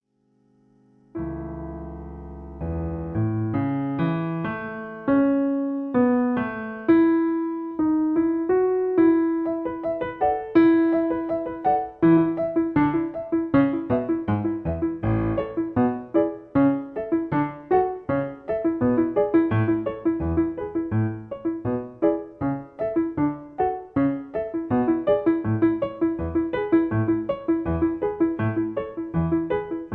Original key. Piano Accompaniment.